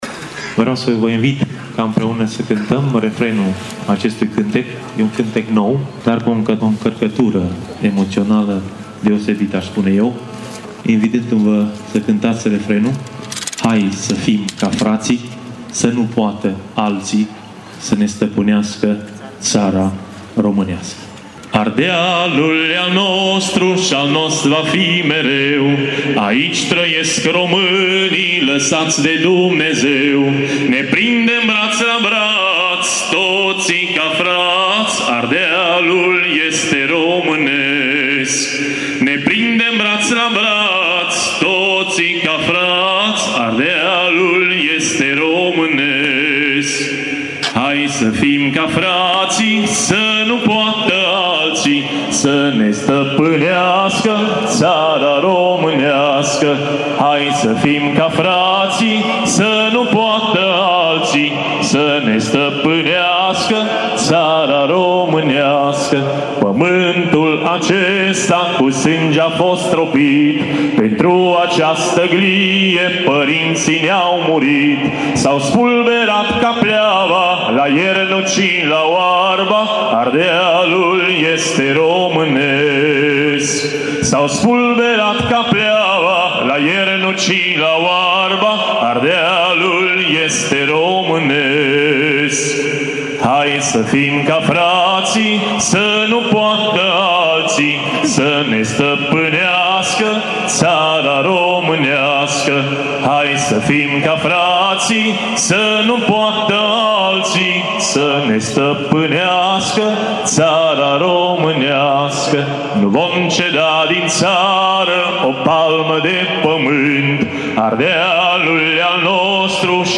un cântec patriotic nou!
Tg Mures 24 ian 2015